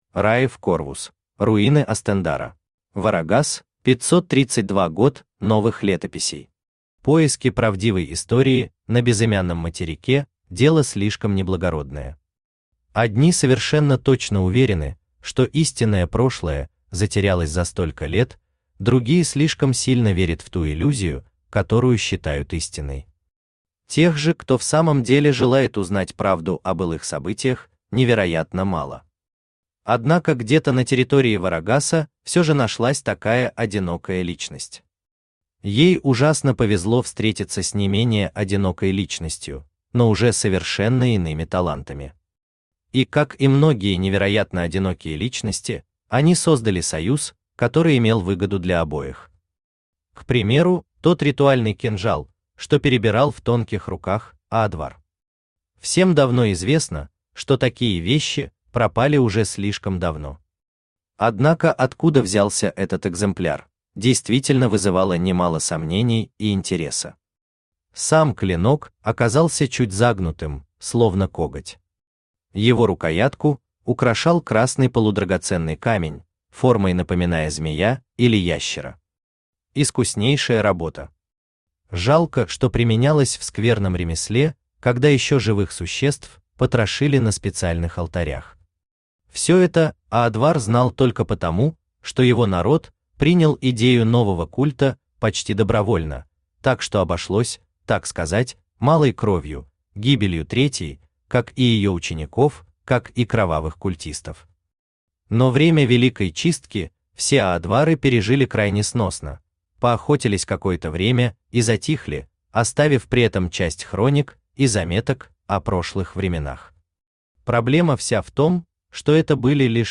Aудиокнига Руины Астендара Автор Райв Корвус Читает аудиокнигу Авточтец ЛитРес. Прослушать и бесплатно скачать фрагмент аудиокниги